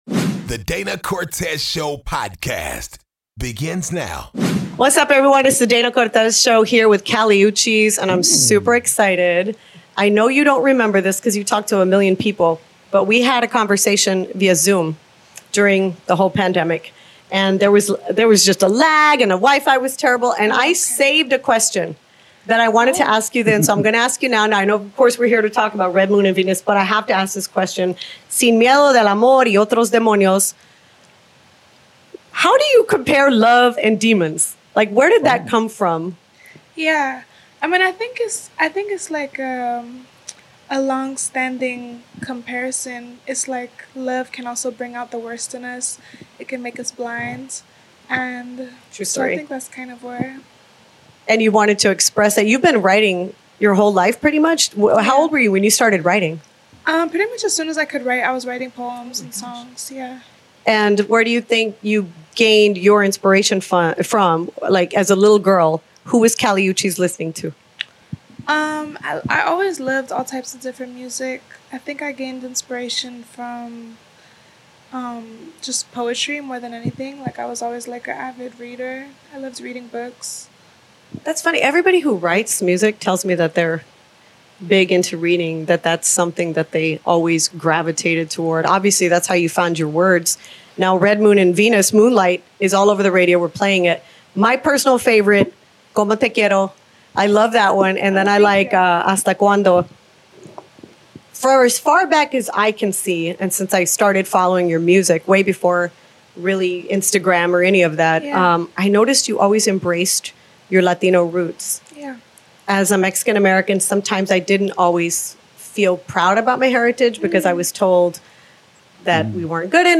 DCS Interviews Kali Uchis